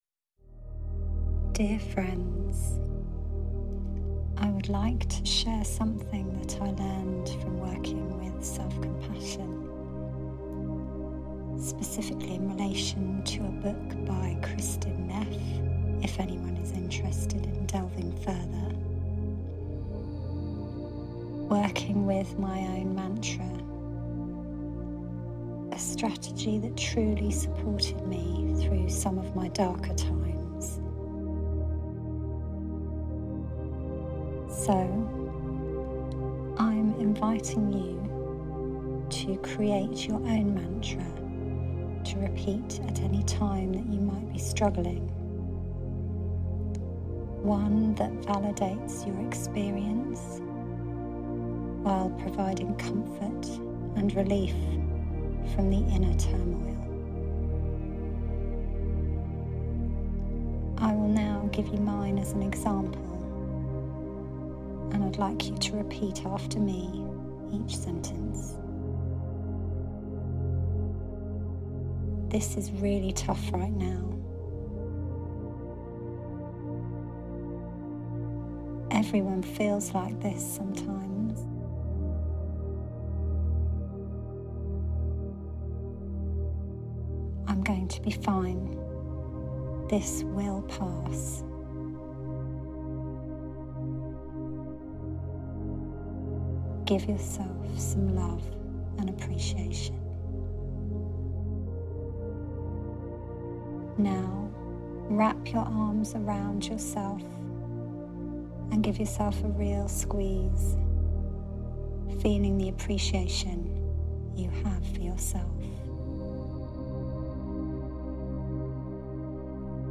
A Portion of Peace Guided Meditation